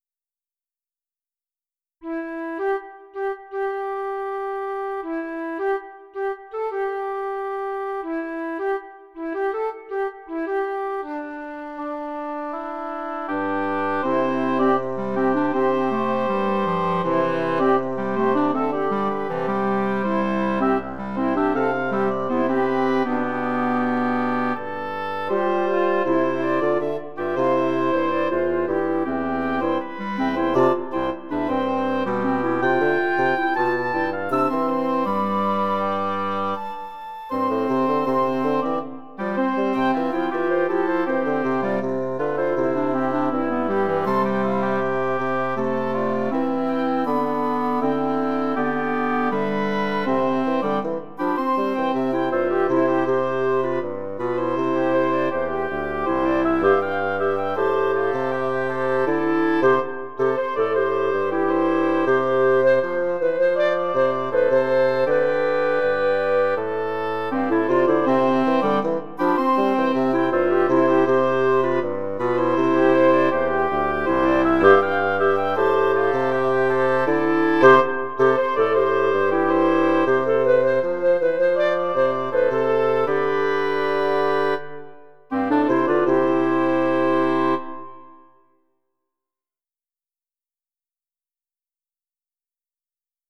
Tags: Quartet, Clarinet, Woodwinds
Title Andante Opus # 180 Year 2006 Duration 00:01:33 Self-Rating 3 Description Designed such that any two or three instruments could play as a duet or trio, omitting the other parts. If the flute is left out, the oboe or clarinet would take its opening solo.
180 Woodwind Quartet.wav